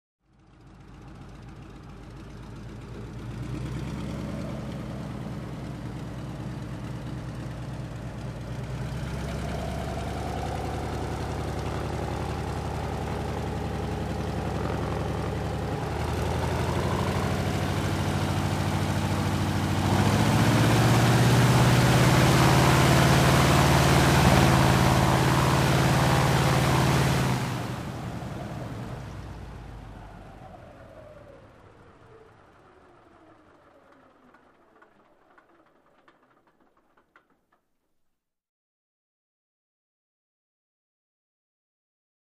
P-47 Mustang Prop Plane Taxi In To Close And Off.